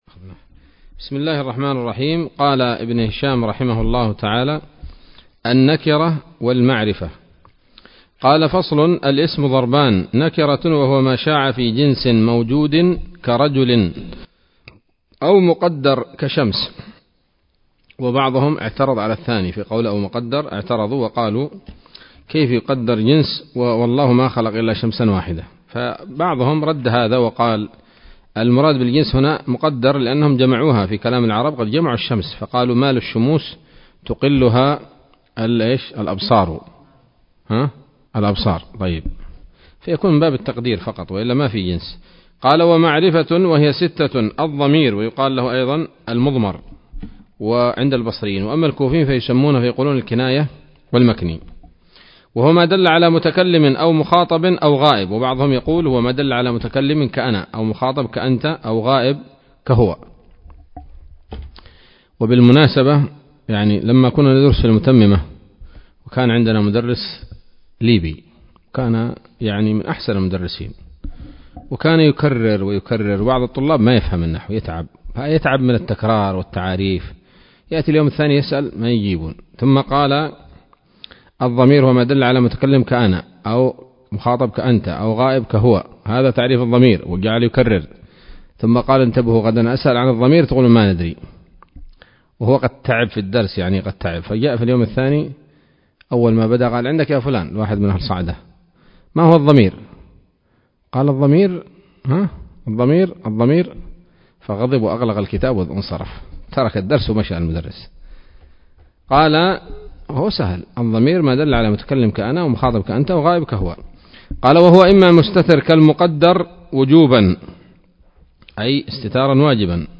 الدرس الثامن والثلاثون من شرح قطر الندى وبل الصدى [1444هـ]